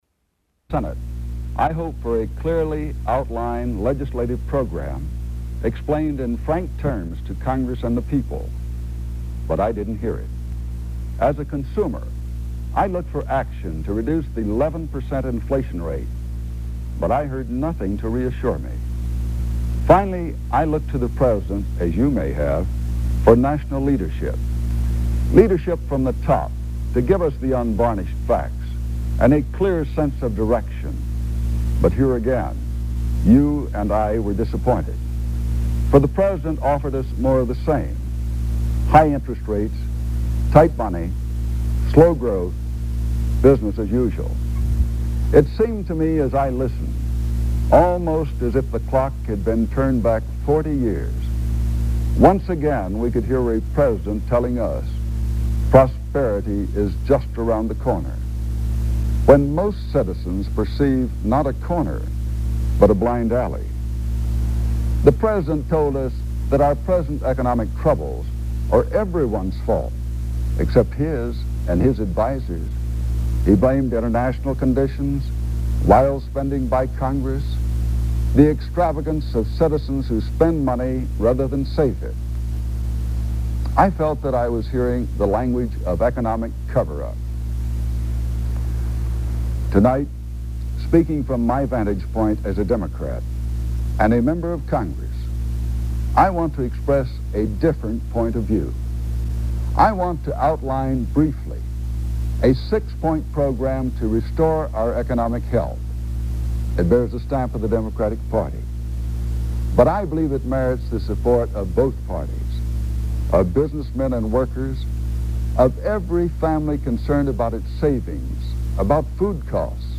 Lloyd Bentsen speaks in the Democrats' equal time reply to President Nixon's economic message